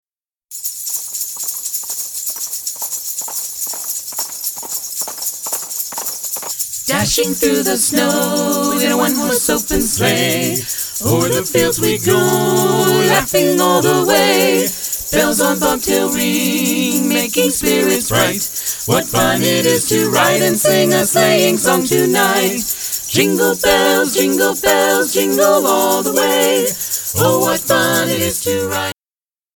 These Sacramento Carolers are comprised of ten members. All sing beautifully and are skilled musicians.
Their clients tell us that they enjoy their rich vocal blend and they can perform over eighty songs.
They have a quality vocal blend that is unequalled.